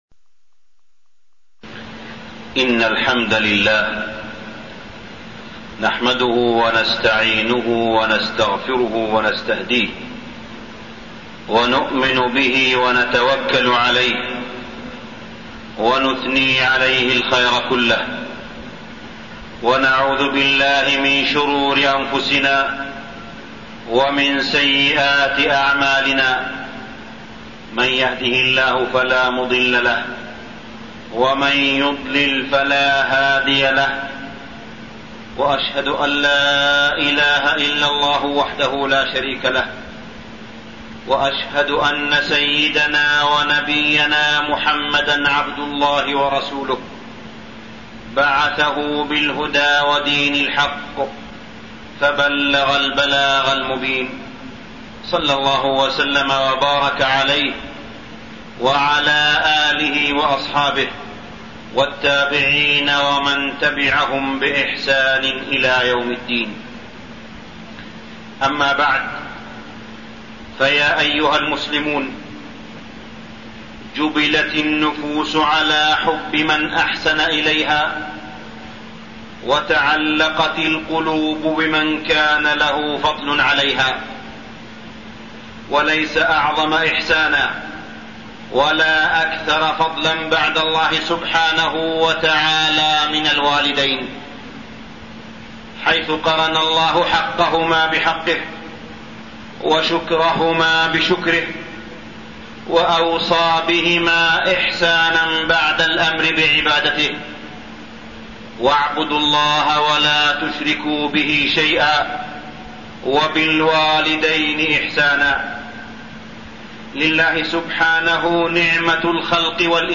تاريخ النشر ٢٦ جمادى الأولى ١٤٠٨ هـ المكان: المسجد الحرام الشيخ: معالي الشيخ أ.د. صالح بن عبدالله بن حميد معالي الشيخ أ.د. صالح بن عبدالله بن حميد رسالة المسجد